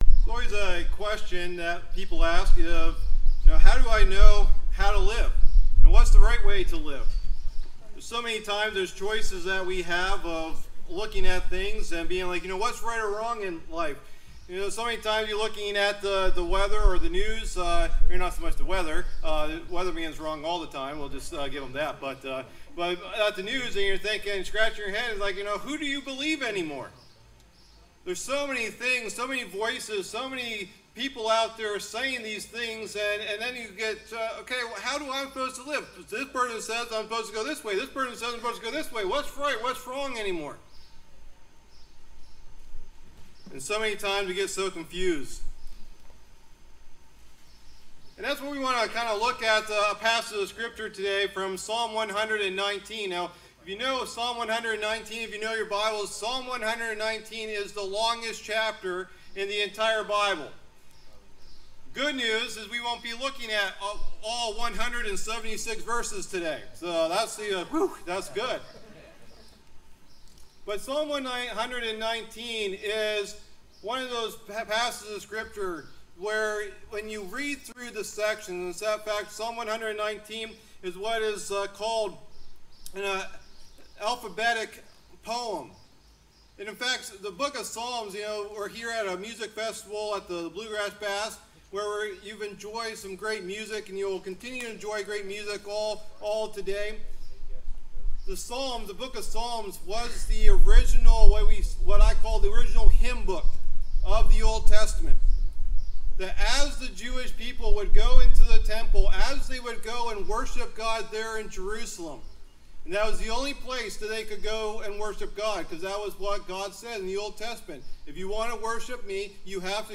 Bedford Bluegrass Bash Sunday Morning service